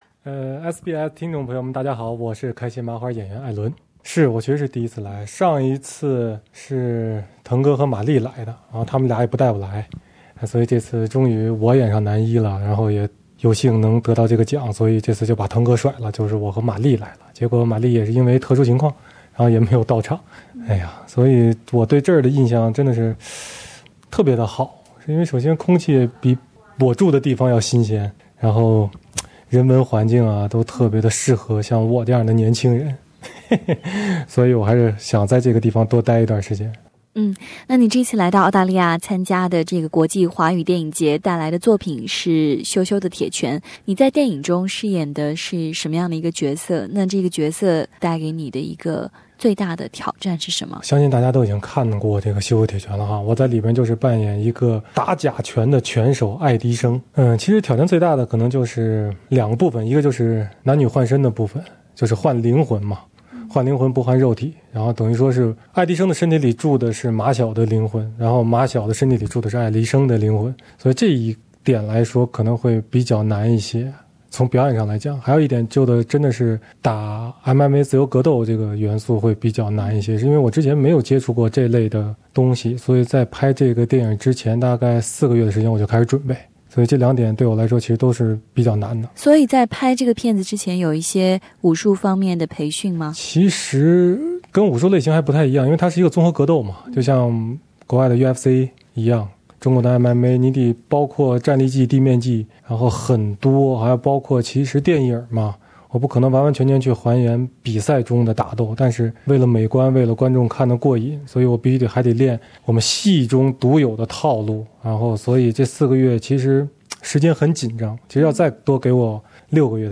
【SBS专访】华语电影节金龙奖最佳男主角艾伦